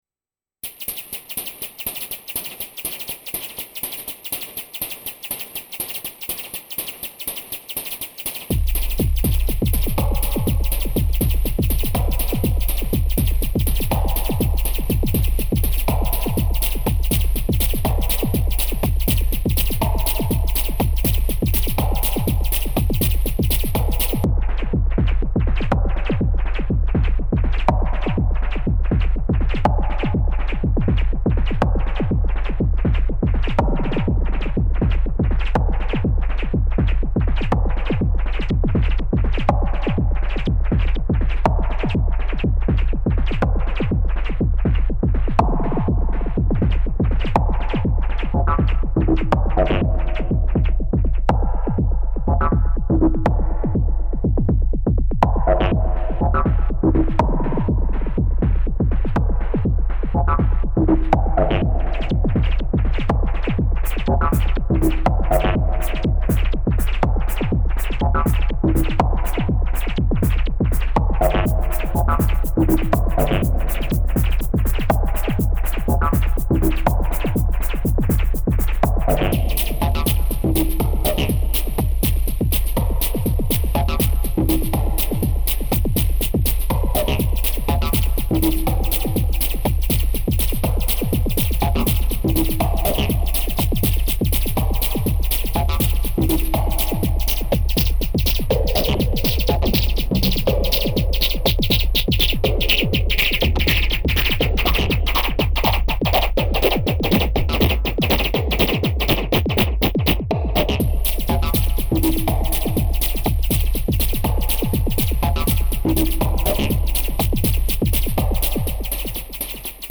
Some kind of bunker techno with triplets and double kick drums. It was tempting to run this through a compressor, but I used the FX track for “pseudo compression” instead.
Two main patterns with a couple of sub-variations each, and song mode for automated pattern switching.